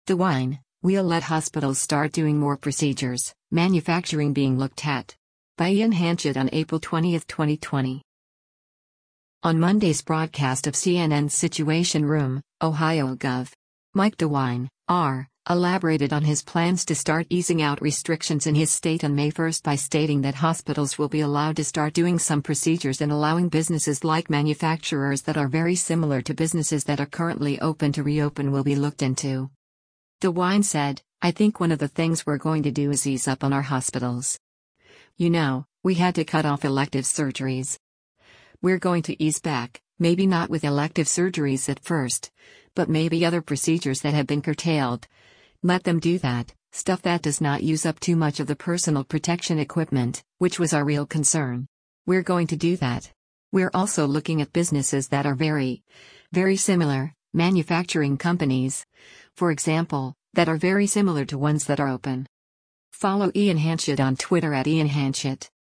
On Monday’s broadcast of CNN’s “Situation Room,” Ohio Gov. Mike DeWine (R) elaborated on his plans to “start easing out” restrictions in his state on May 1 by stating that hospitals will be allowed to start doing some procedures and allowing businesses like manufacturers that are “very similar” to businesses that are currently open to reopen will be looked into.